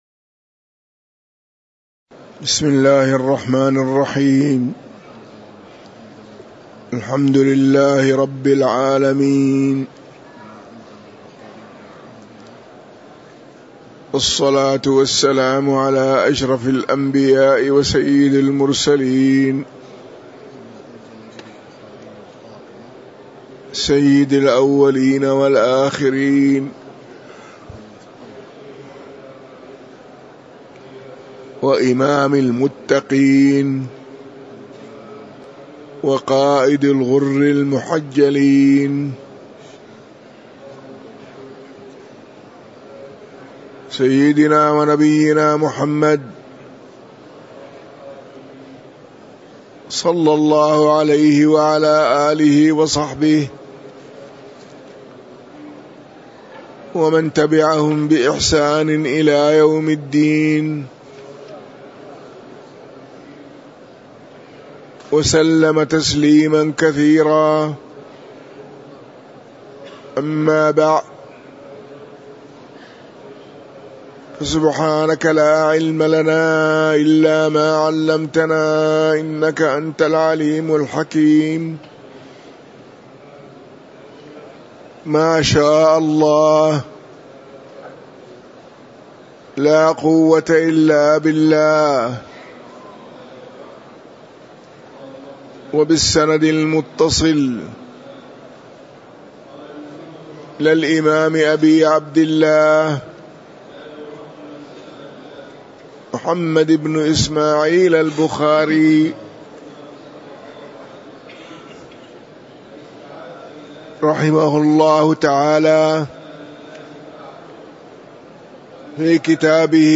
تاريخ النشر ٢٦ ربيع الثاني ١٤٤٤ هـ المكان: المسجد النبوي الشيخ